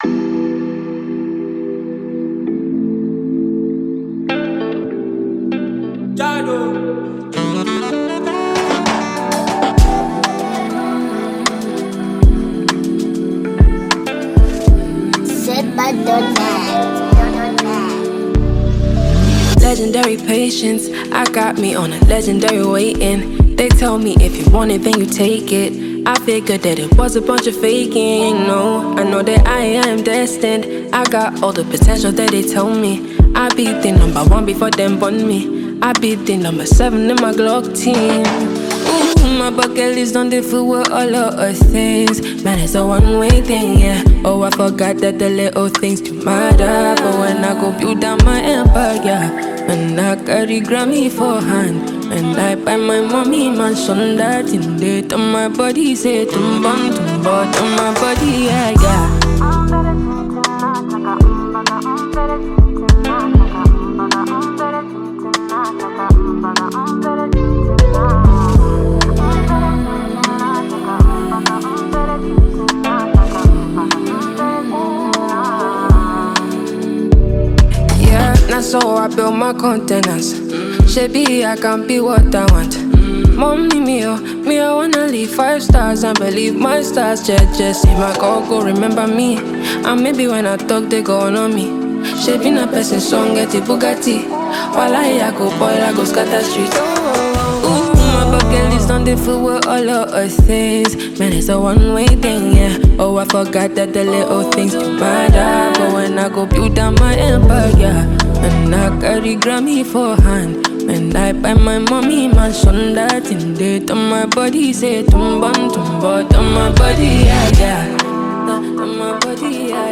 Genre: Afrobeat